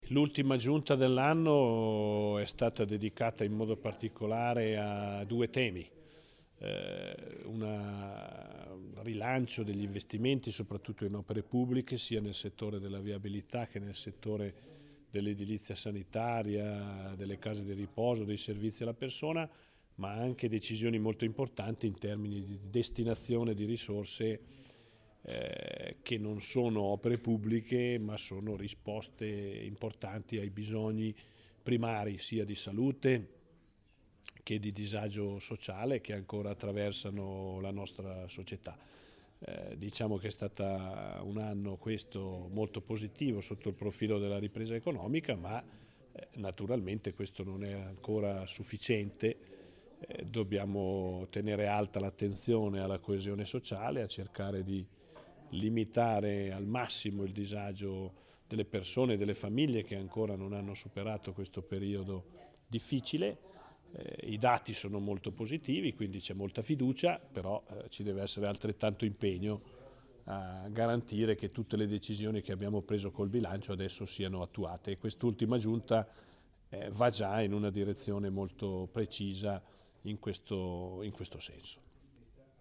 dichiarazione_ROSSI_conf._stampa_28_dic.mp3